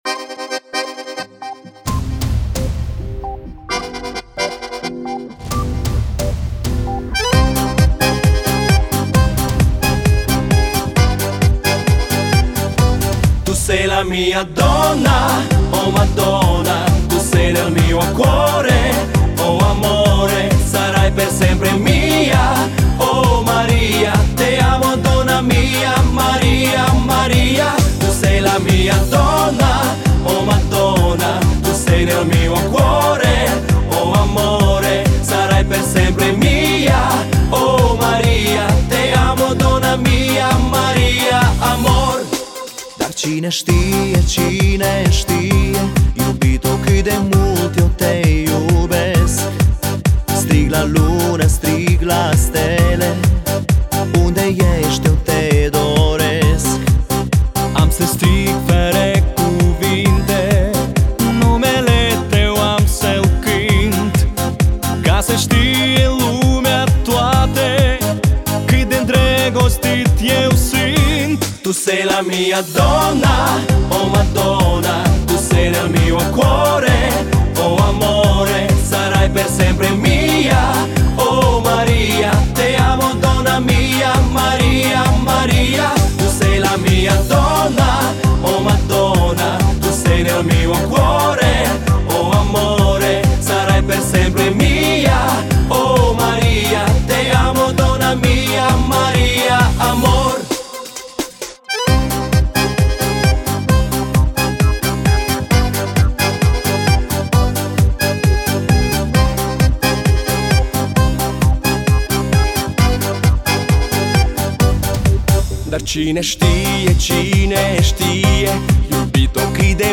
мне слишится Баян- Мix и какая-то молдавская подпевка
(Поп)
Группа молдавская.